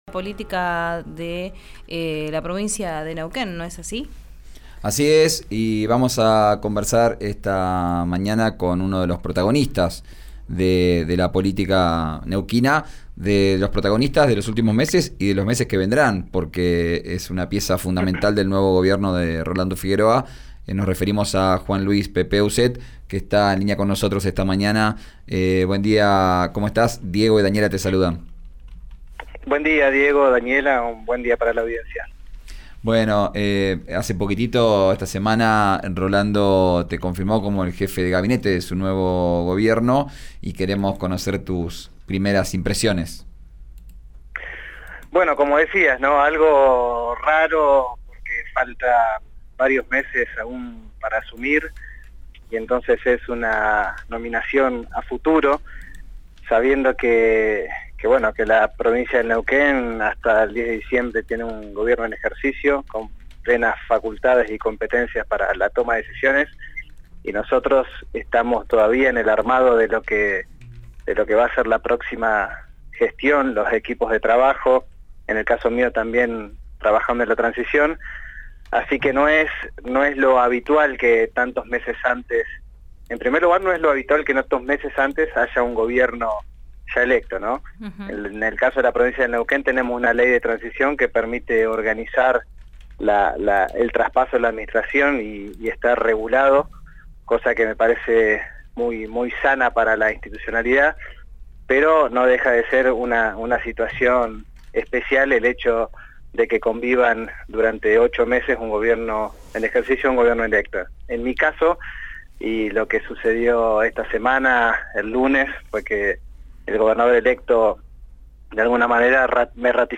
En diálogo con «Vos Al Aire» de RÍO NEGRO RADIO, Ousset habló sobre su designación en el próximo gabinete y aseguró que no estaba al tanto del anuncio que hizo el gobernador electo este lunes a la prensa.